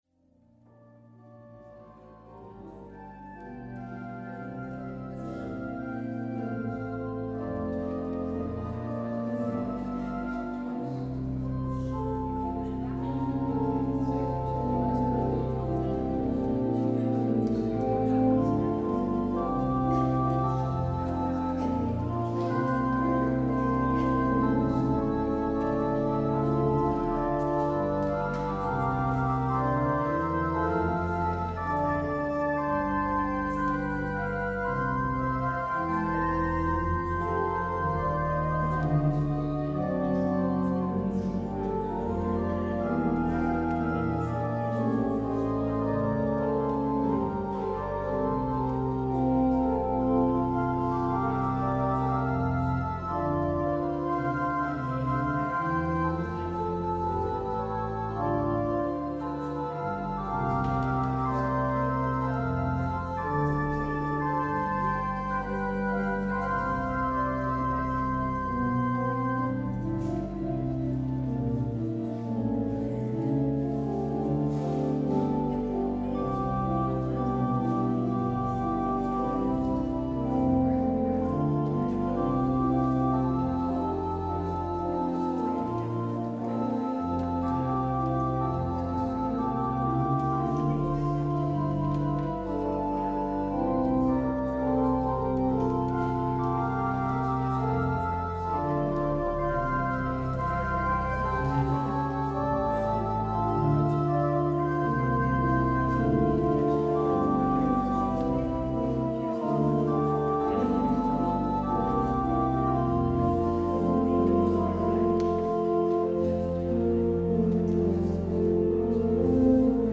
Het openingslied is: Gezang 448: 1 en 4.
Het slotlied is: Psalm 121:1,4.